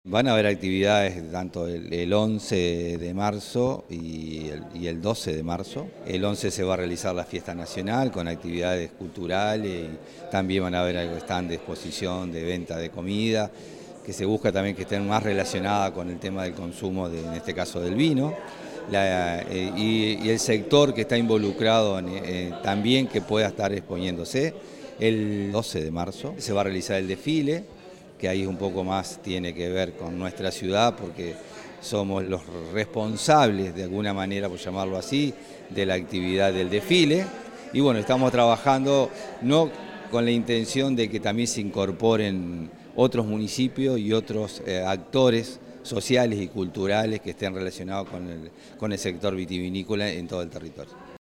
En la sede del Instituto Nacional de Vitivinicultura (INAVI) se presentaron las diversas actividades de una nueva edición de la Fiesta de la Vendimia 2023, evento que se desarrollará el próximo sábado 11 y domingo 12 de marzo en la plaza Batlle y Ordóñez de la ciudad de Las Piedras, capital de la uva y el vino.
alcalde_gustavo_gonzalez_1.mp3